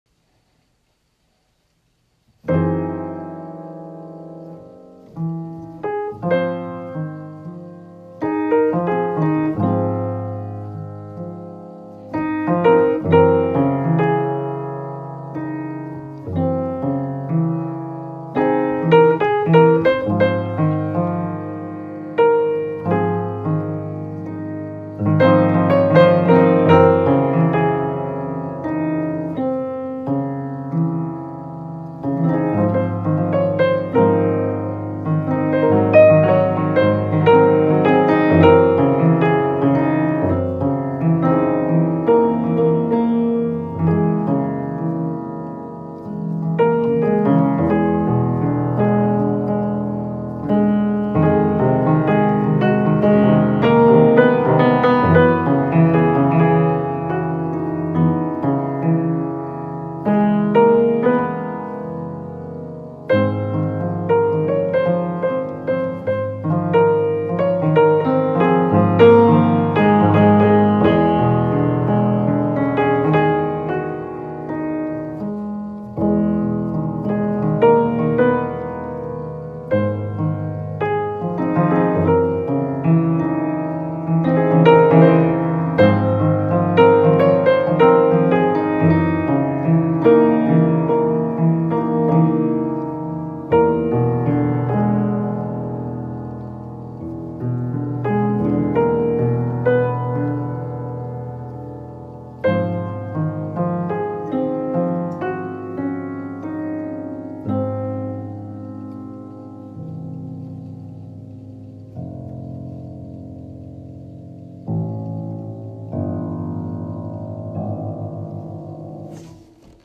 He took up piano playing in his 40s and sent me this original composition he called “Clouds”. His favorite jazz pianist was Earl Hines and I think there are some of his influences here.
• “I was looking out the window on a gloomy day.